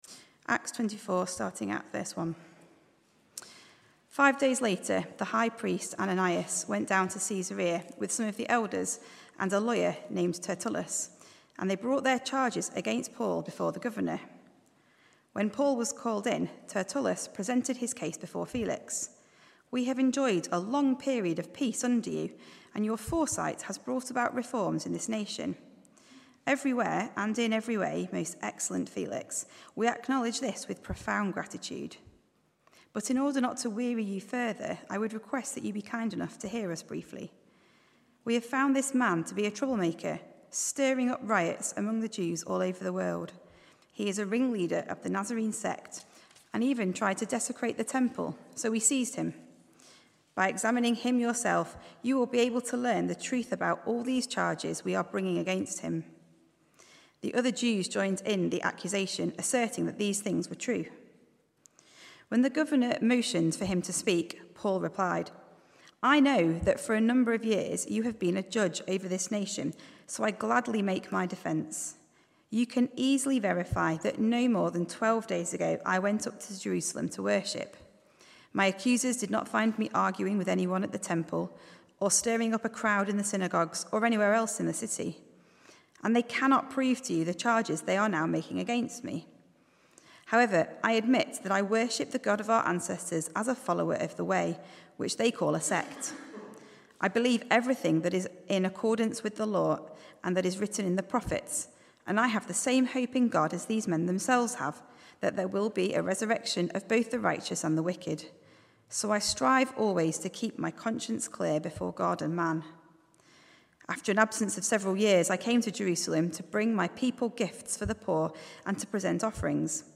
Sermons Archive - Page 20 of 187 - All Saints Preston